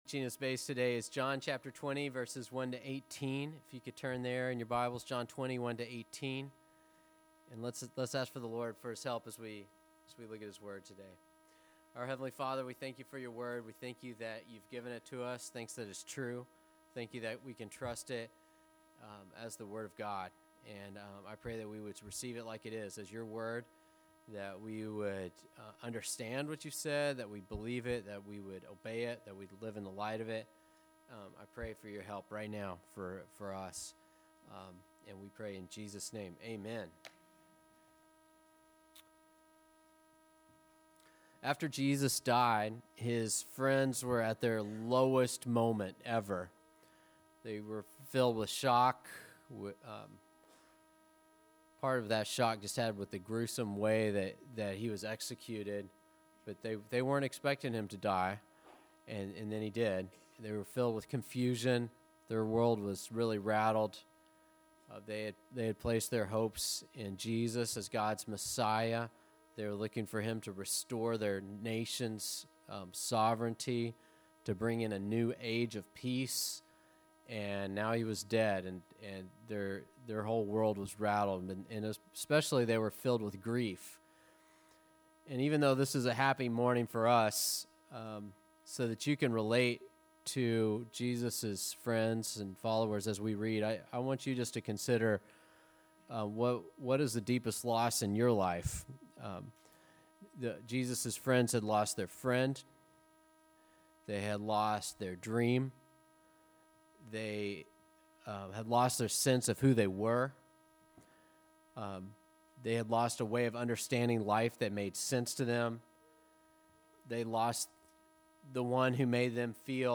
Listen to sermons by our pastor on various topics.